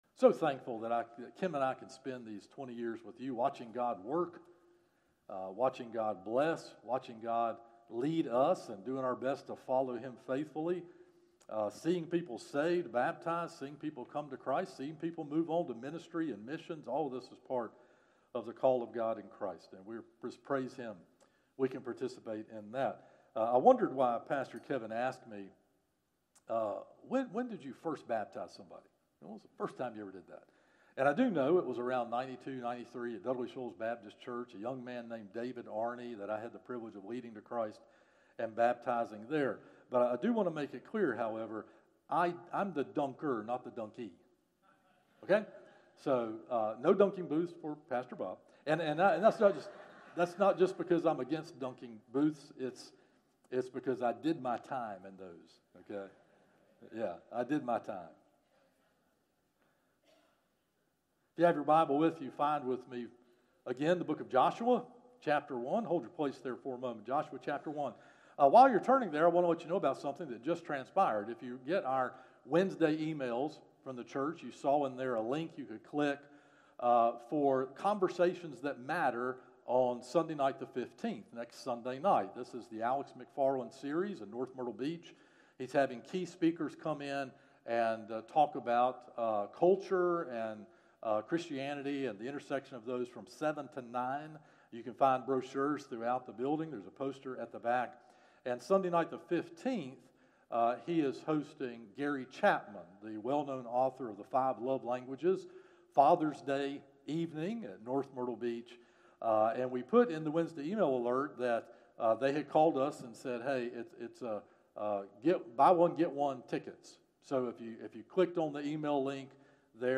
Worship-Service_-Where-Courage-Comes-From.mp3